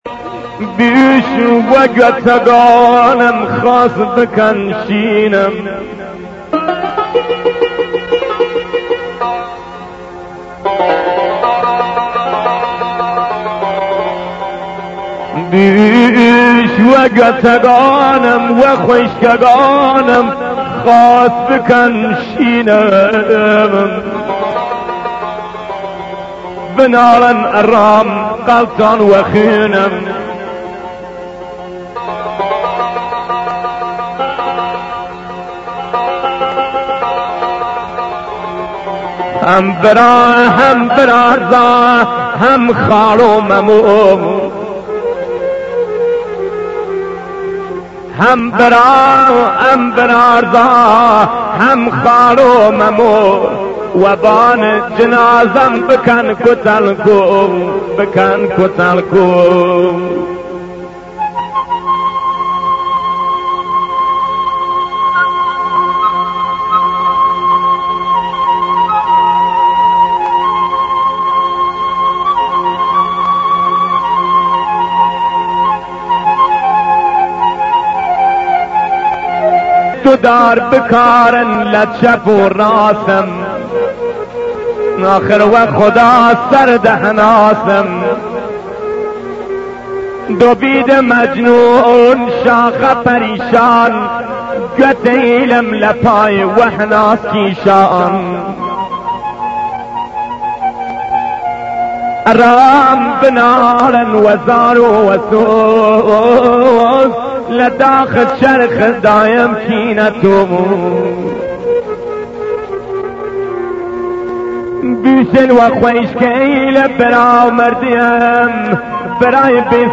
مویه کردی
آهنگ گریه دار کردی فاتحه خوانی